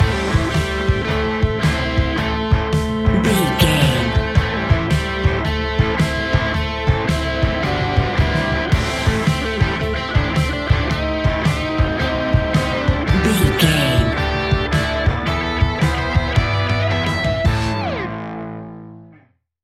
Ionian/Major
hard rock
blues rock
distortion